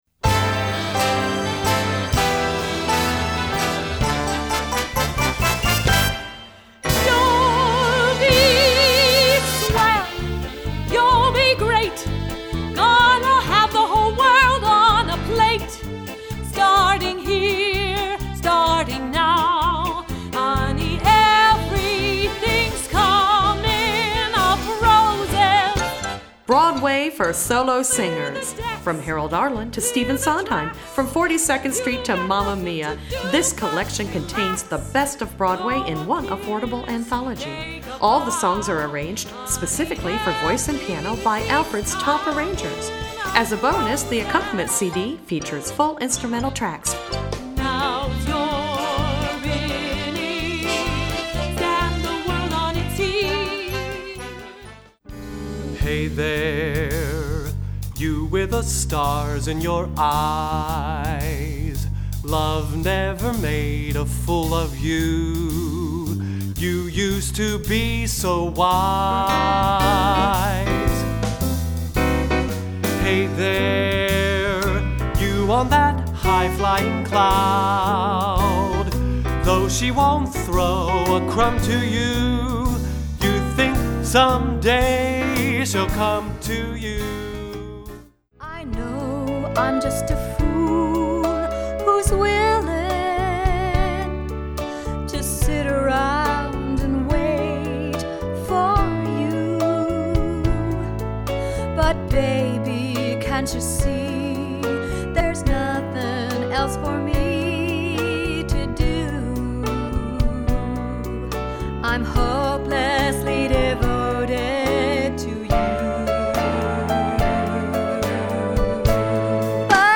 Voicing: Vocal Solo